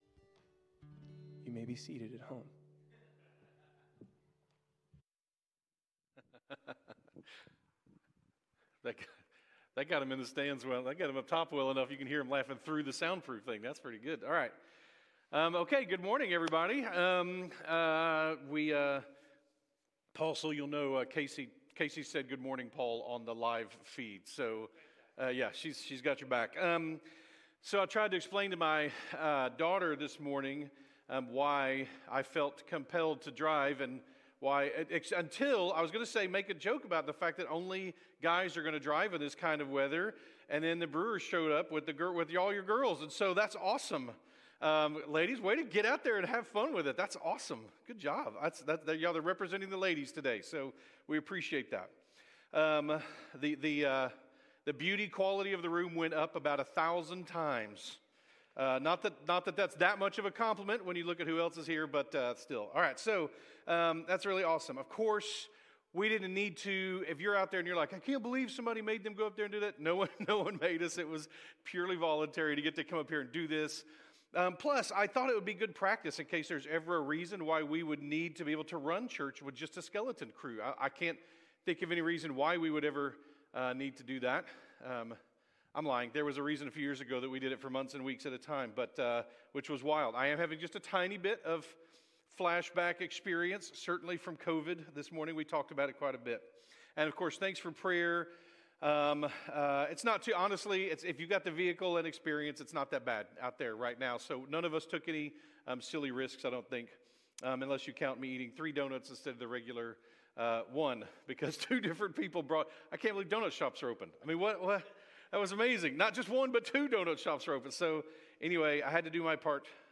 by South Spring Media | Jan 28, 2026 | Isaiah, Isaiah Series, Scripture, Series, Sermons | 0 comments